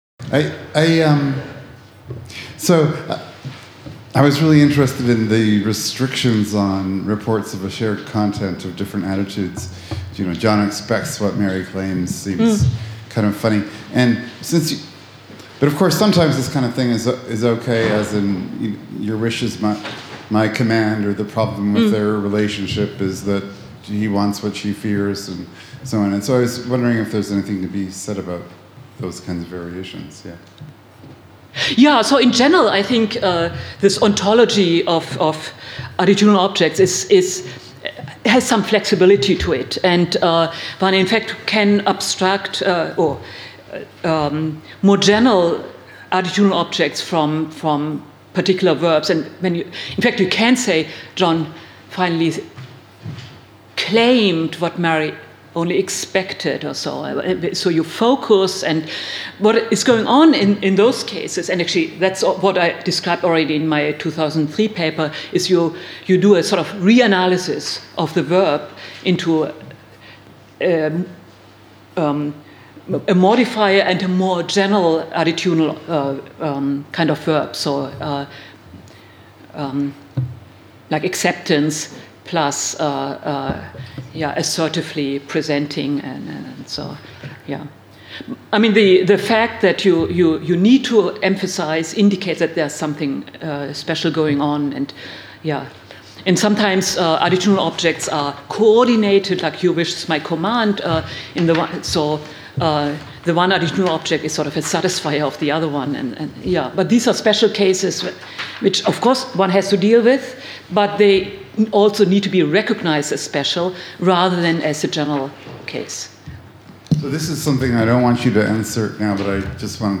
Discussion (2) | Collège de France